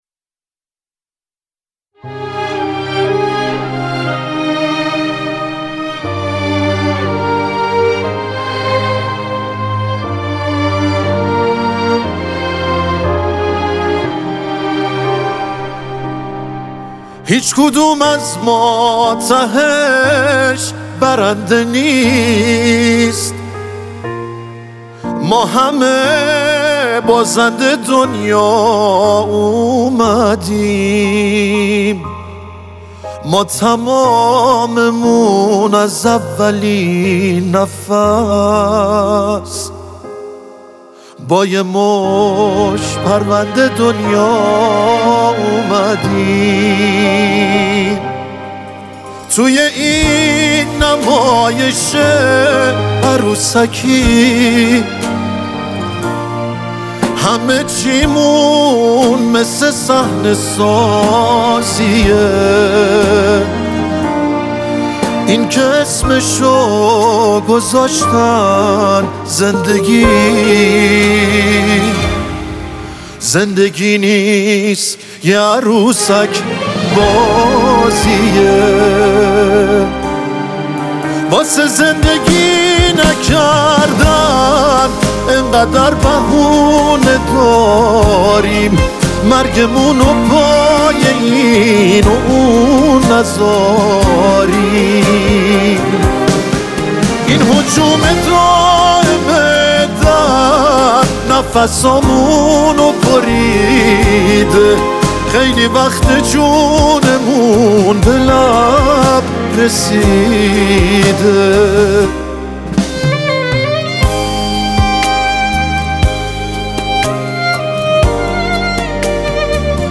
غم خاصی توی صداشه